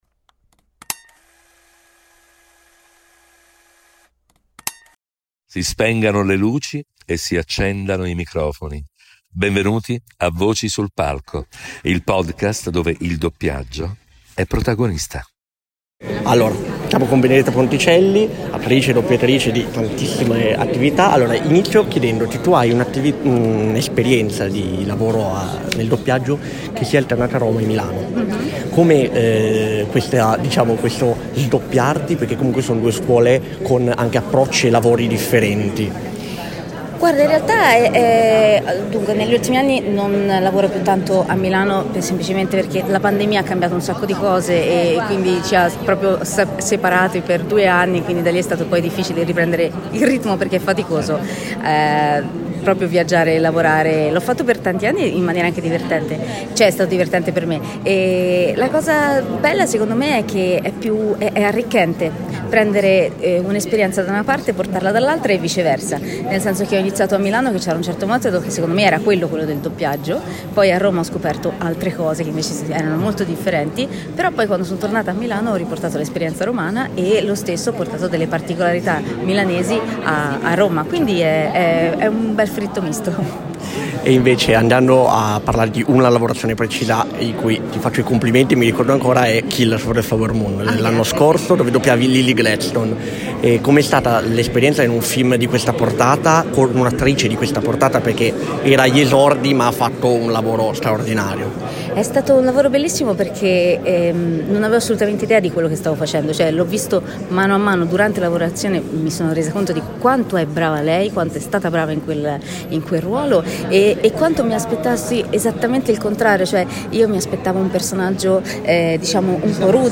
Interviste esclusive